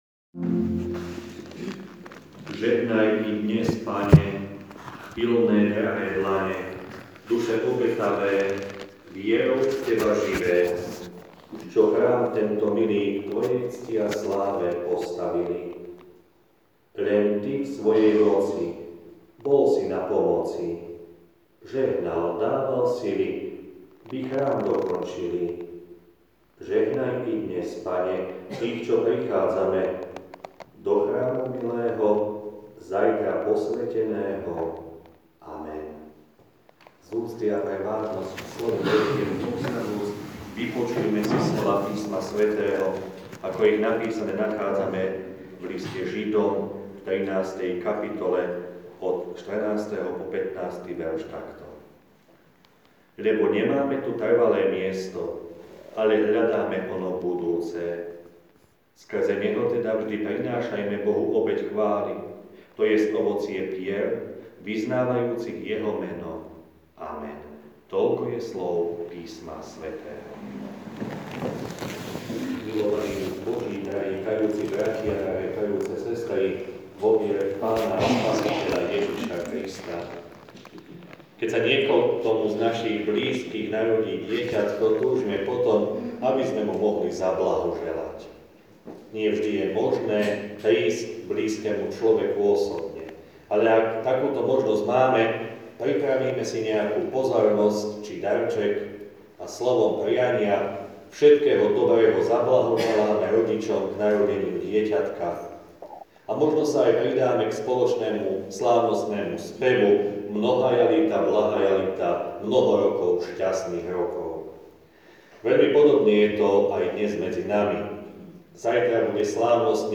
Slávnosť posviacky novostavby